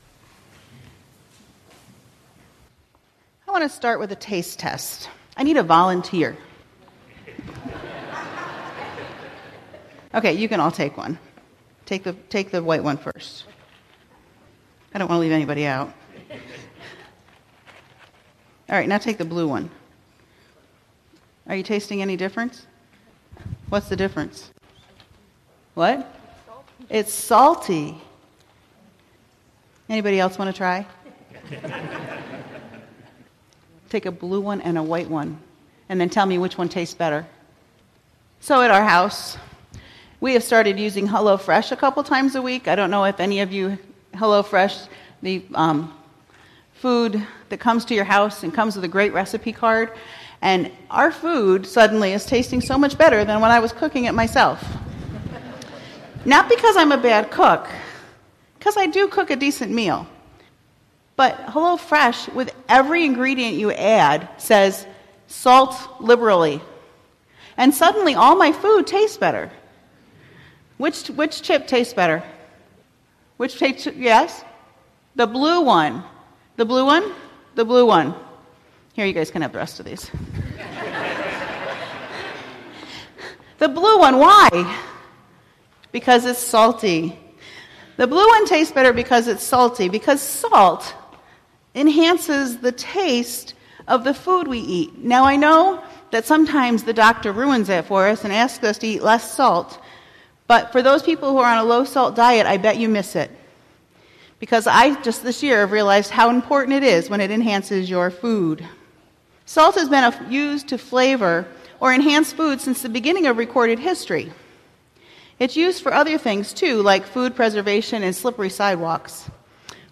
2019-05-05 Sermon, “Spicy and Bright”